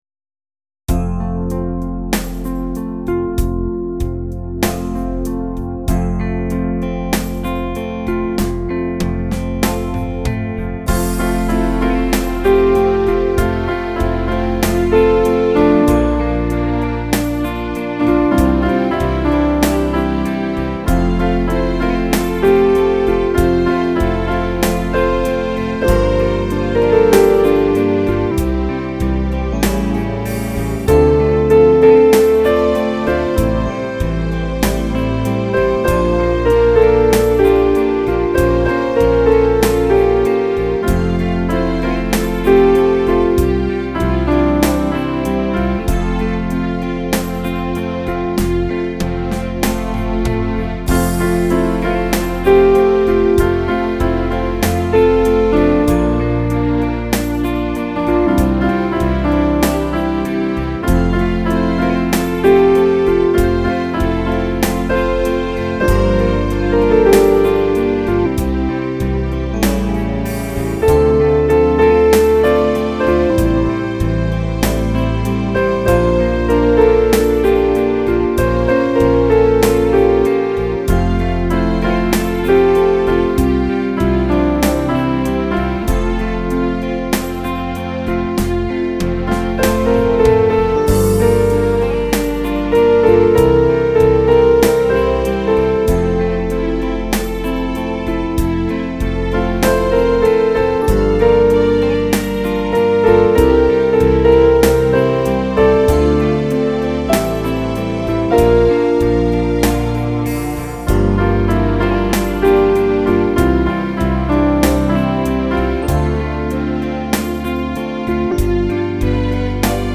Instrumentalaufnahme